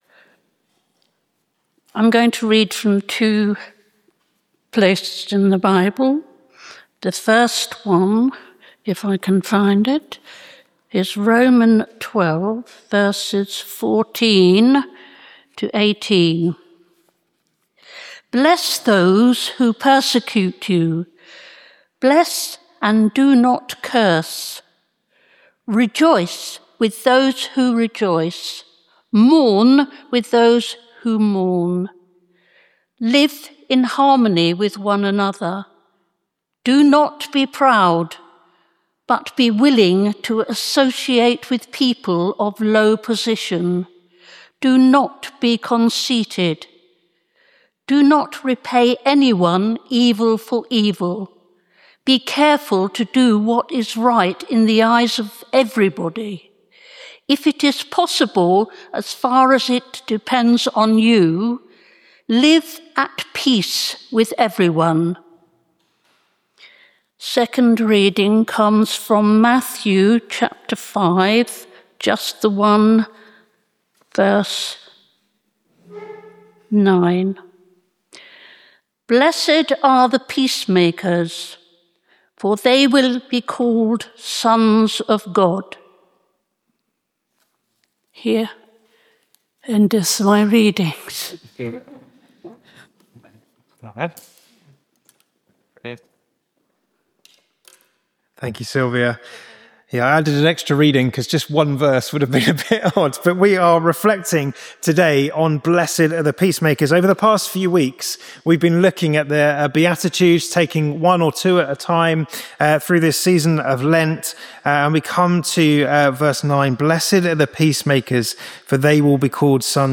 Location: St Mary’s, Slaugham
Service Type: Communion